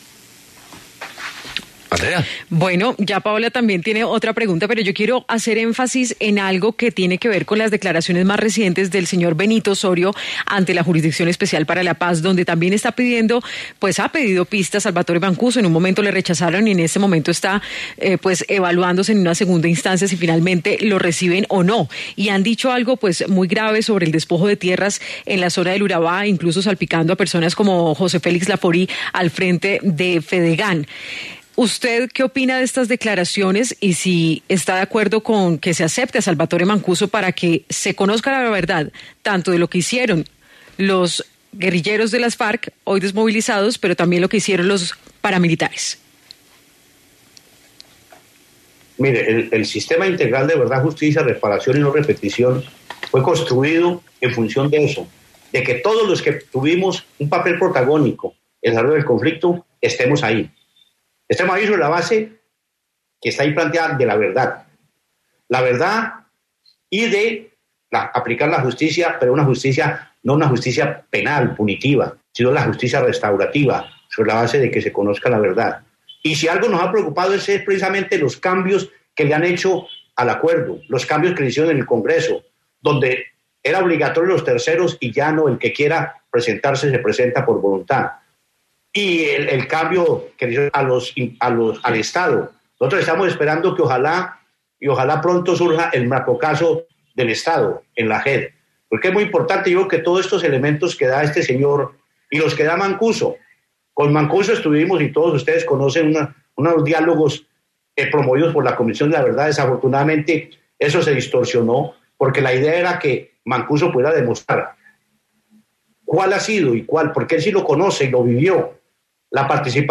Rodrigo Londoño, presidente del Partido Comunes y líder de las desmovilizadas Farc, habló en Sigue La W sobre la situación de seguridad de los firmantes del acuerdo y posibilidades de nuevos diálogos sobre el conflicto.